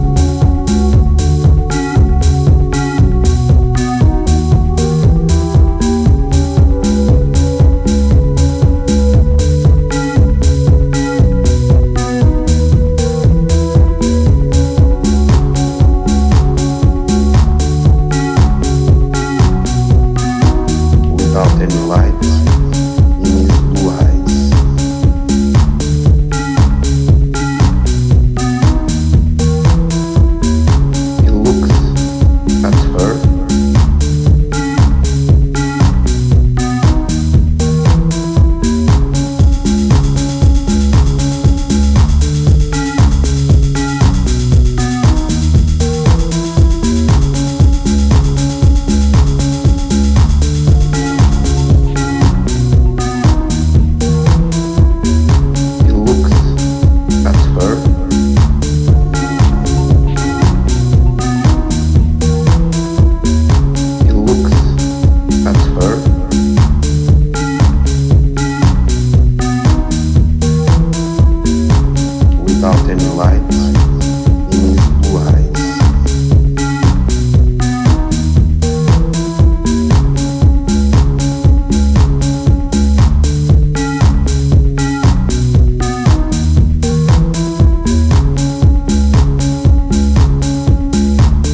hallucinatory B-side